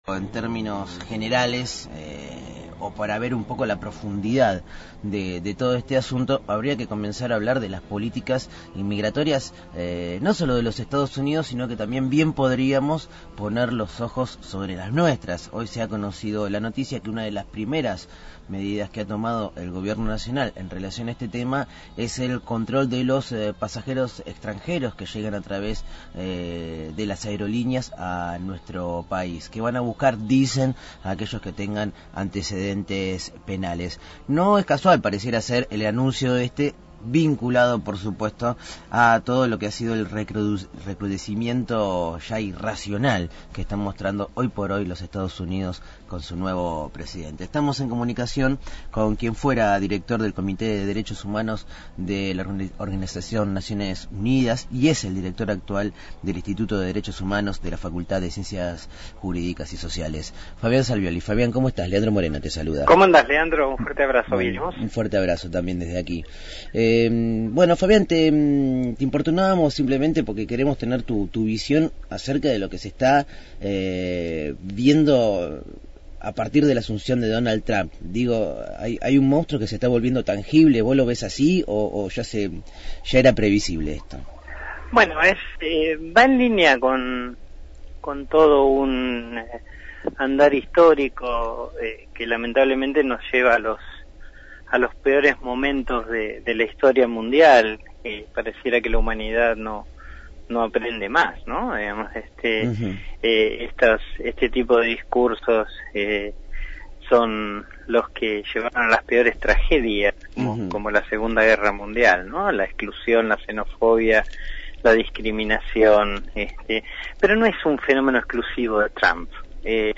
Entrevista a Fabián Salvioli, presidente del Comité de Derechos Humanos de la ONU, sobre la situación inmigratoria argentina y las medidas de Donald Trump en EEUU. Programa: Verano Fatal.